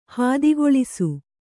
♪ hādigoḷisu